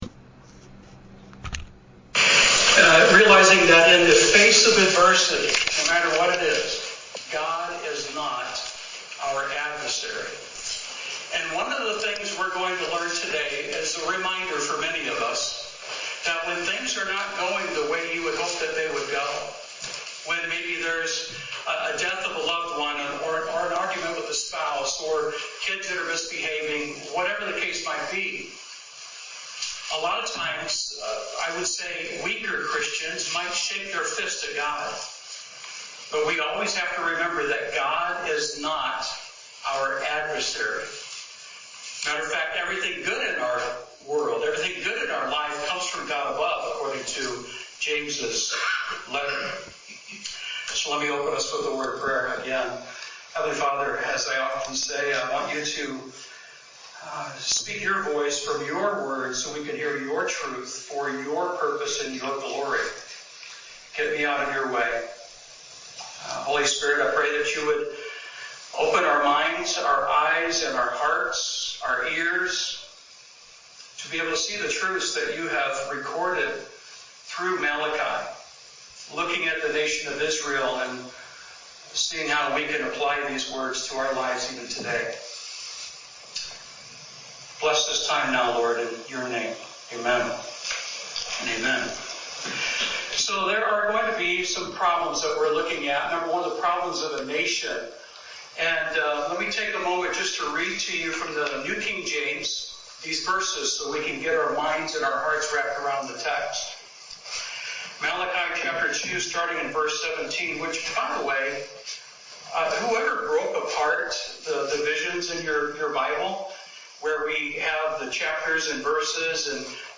The recording of this sermon has now been added.